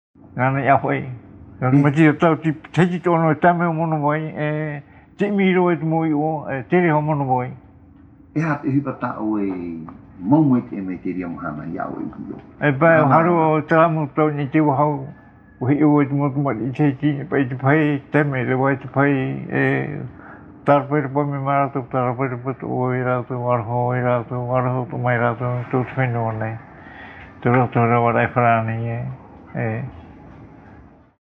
Interview réalisée à Tīpaerui sur l’île de Tahiti.
Papa mātāmua / Support original : cassette audio